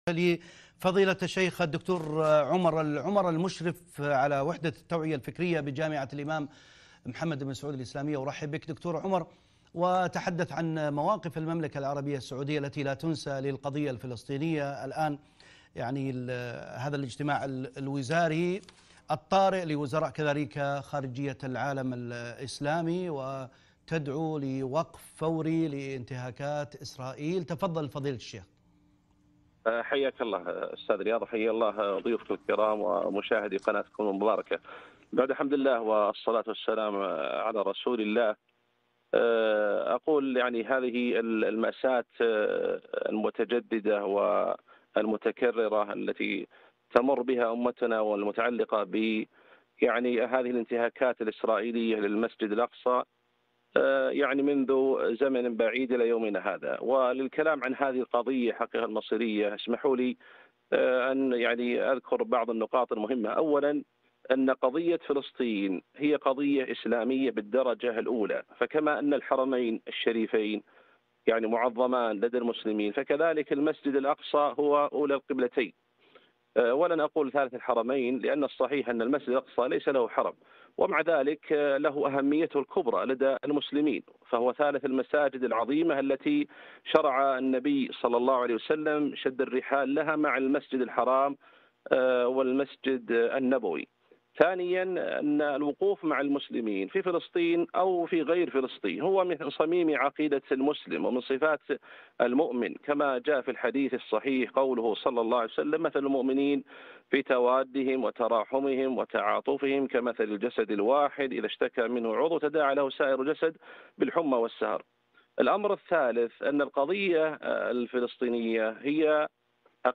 لقاء إذاعي - وقفات مع القضية الفلسطينية